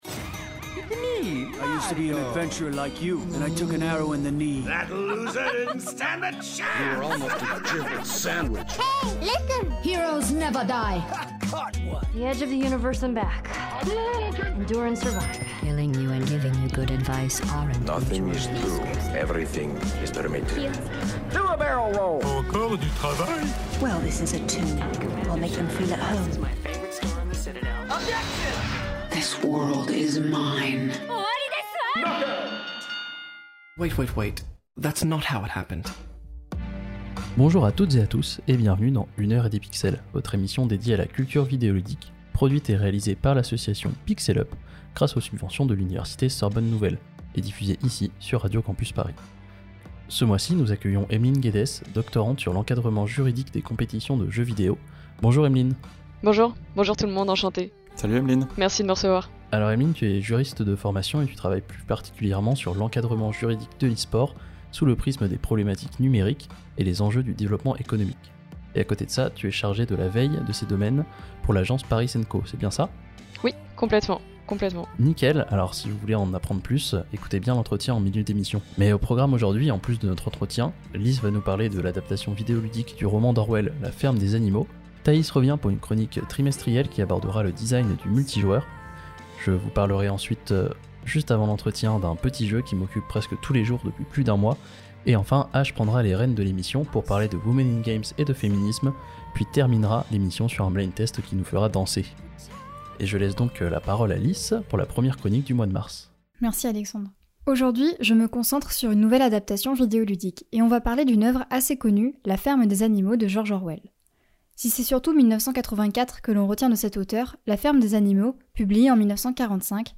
Une heure et des pixels — S3Em07 — Entretien avec...